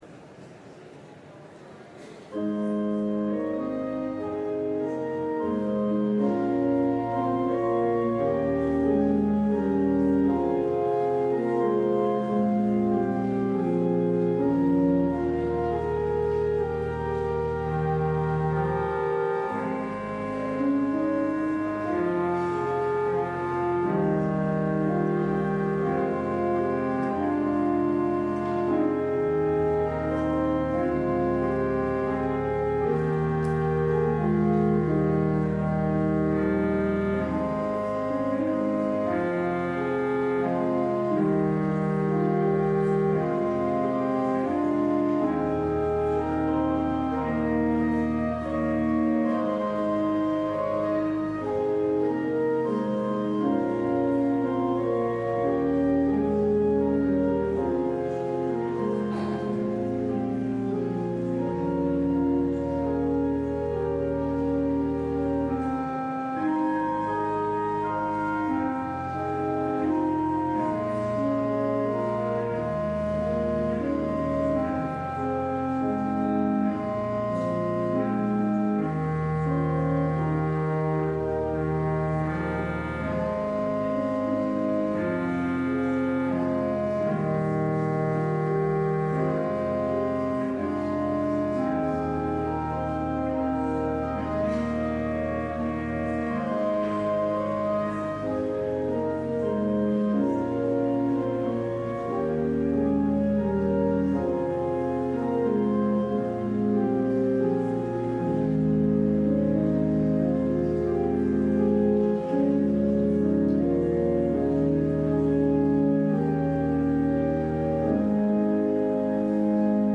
LIVE Morning Worship Service - Faith 101: The Bible